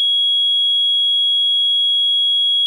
grenade_tick_finish.wav